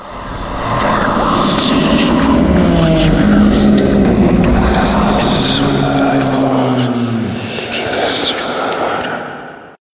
zombie_idle_1.ogg